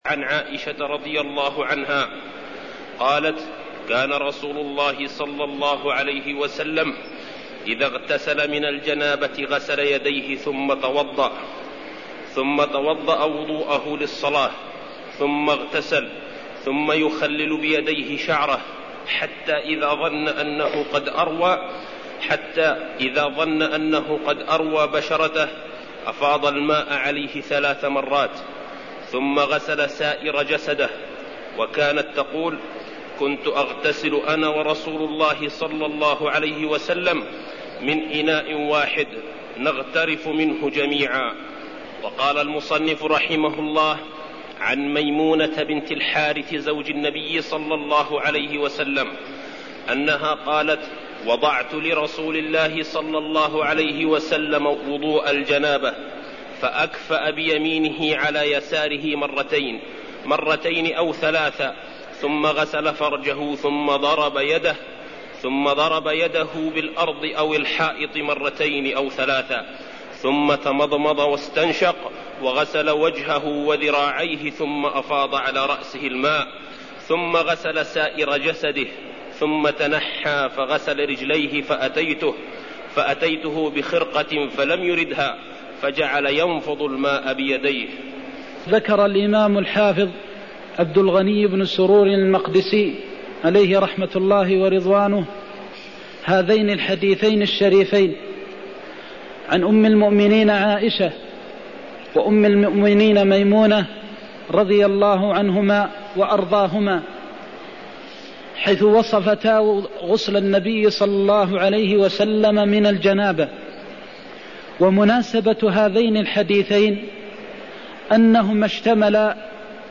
المكان: المسجد النبوي الشيخ: فضيلة الشيخ د. محمد بن محمد المختار فضيلة الشيخ د. محمد بن محمد المختار صفة الغسل من الجنابة (30)